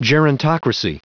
Prononciation du mot gerontocracy en anglais (fichier audio)
Prononciation du mot : gerontocracy